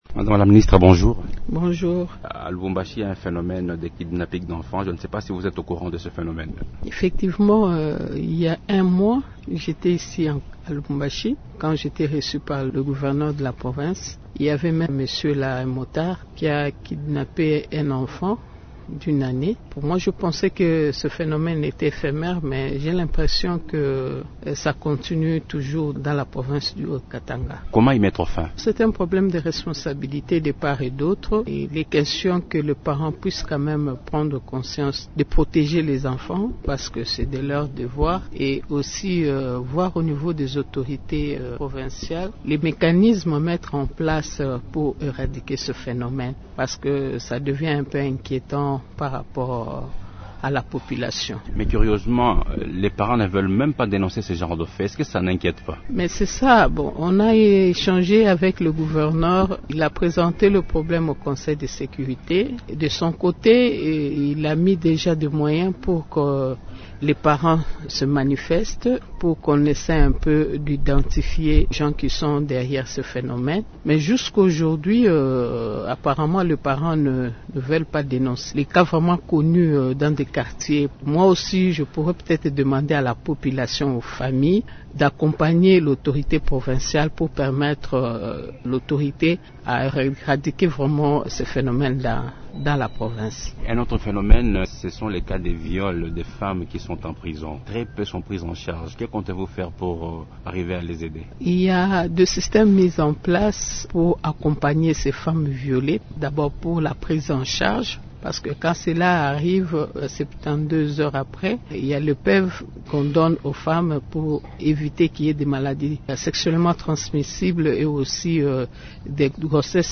Invitée de Radio Okapi lundi 30 août 2021 à l’occasion de son passage à Lubumbashi, Gisèle Ndaya demande également aux parents de bien surveiller leurs enfants afin de barrer la route à ces kidnappeurs.